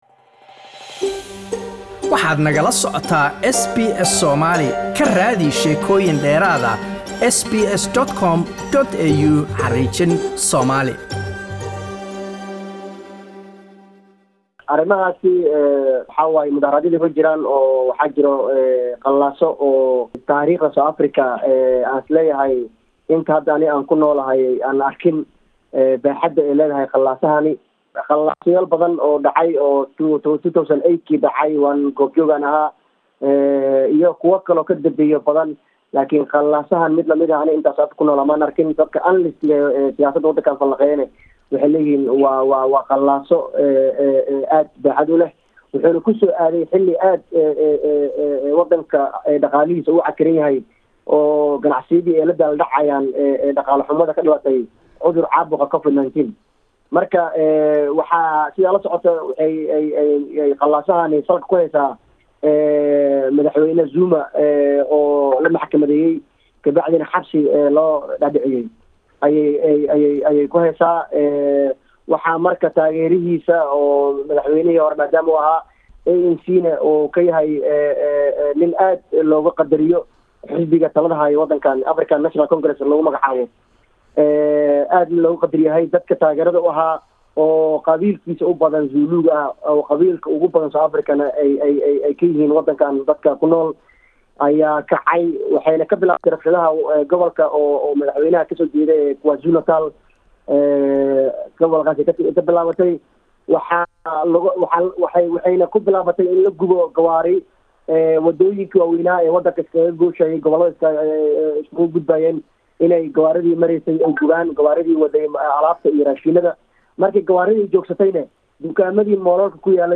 Waraysi: Kacdoonno Soomaali ku dhimatay oo ka dhacay South Africa